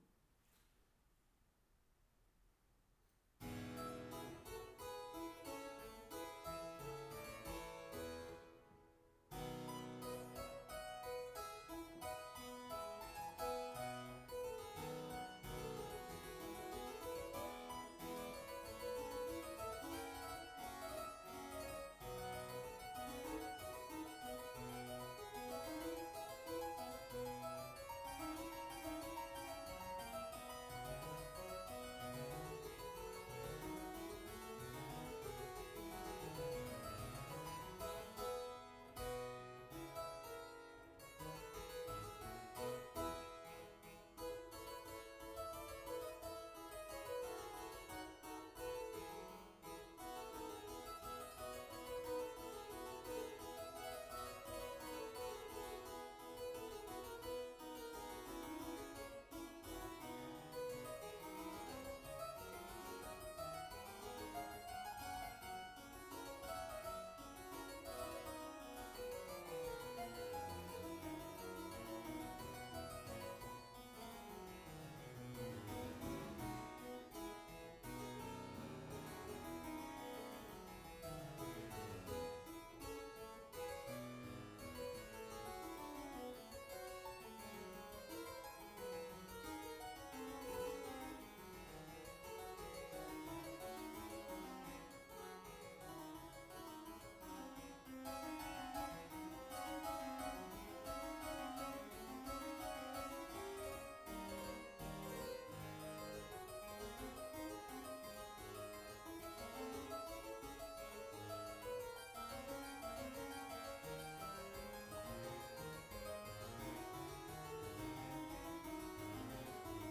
バロック発表会2023　チェンバロを演奏しました